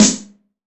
SNARE 077.wav